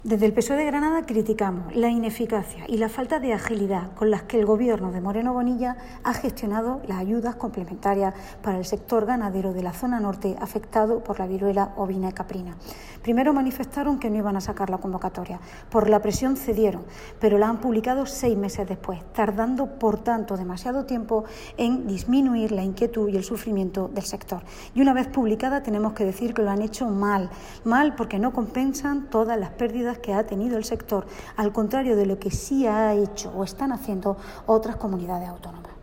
El PSOE critica “la ineficacia de la Junta para atenuar el sufrimiento e inquietud de los ganaderos de la zona Norte afectados por la viruela”. Así se titula el comunicado que nos ha remitido el PSOE granadino, el cual reproducimos a continuación, junto a la imagen y el corte de voz que lo acompañan:
“Debería haber incluido la compensación de todas las pérdidas como hacen otras comunidades autónomas, pero tampoco lo han hecho pese a que lo hemos demandado en numerosas ocasiones”, ha subrayado la parlamentaria andaluza Olga Manzano.